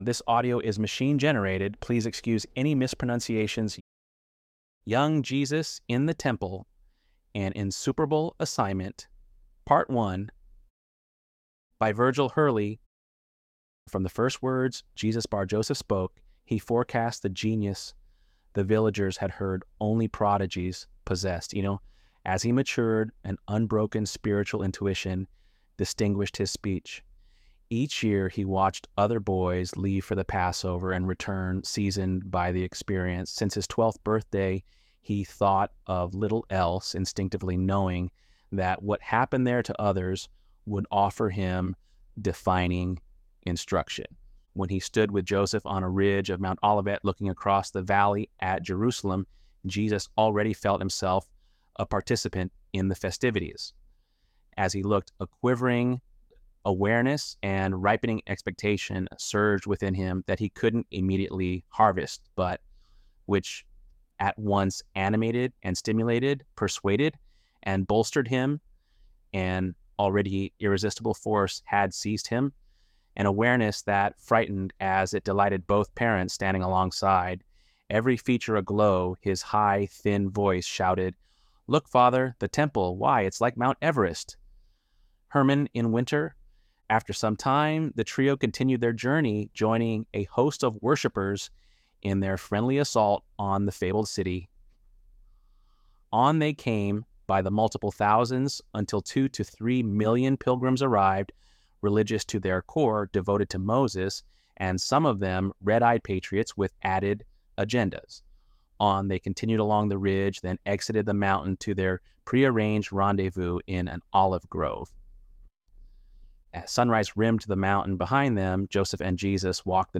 ElevenLabs_10.7.mp3